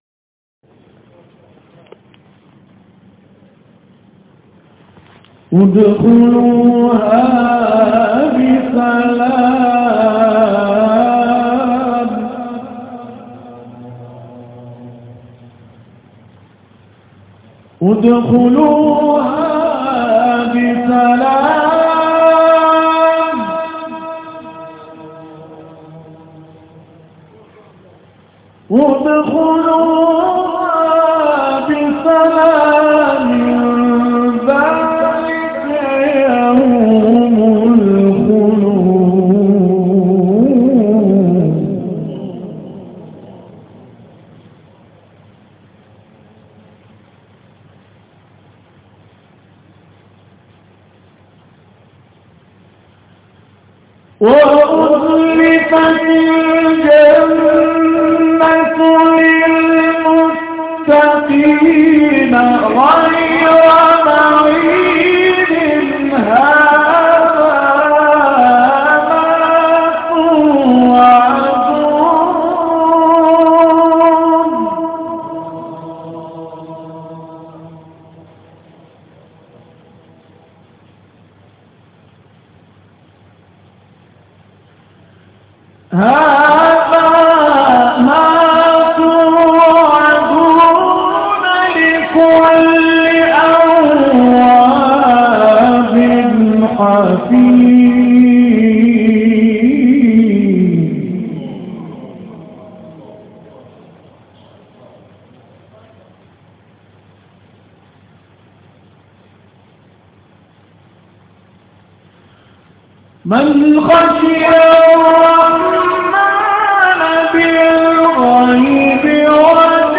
گروه فعالیت‌های قرآنی: محفل انس با قرآن کریم در مسجد حضرت علی بن ابیطالب(ع) اراک با حضور احمد ابوالقاسمی برگزار شد.
حسن ختام این محفل، تلاوت احمد ابوالقاسمی بود که در ادامه ارائه می‌شود.